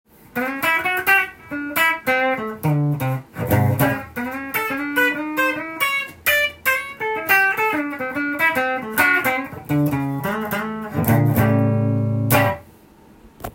３３５タイプのセミアコです。
更にリアピックアップでも弾いてみました。
やはり鳴りがいいので、リアではなんとジャズではなく、
ブルースが合いそうなサウンドです。